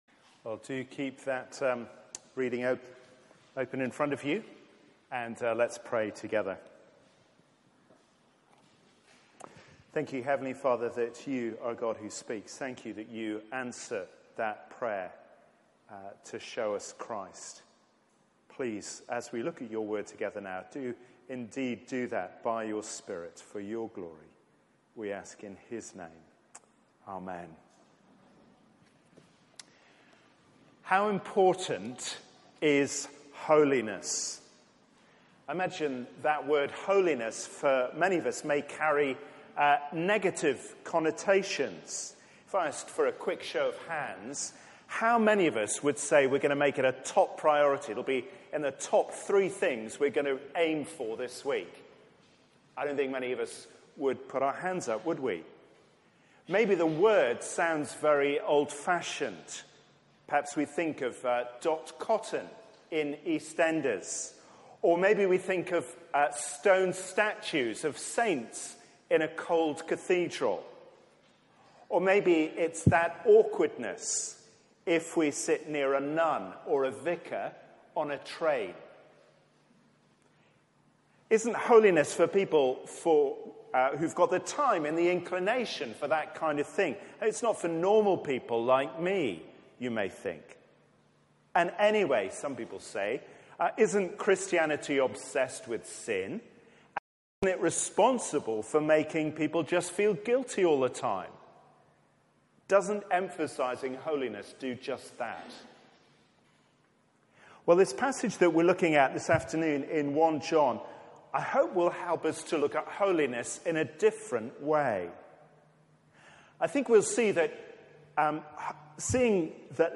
Media for 4pm Service on Sun 22nd Apr 2018 16:00
Series: Know you have Eternal Life Theme: As you obey God's commands Sermon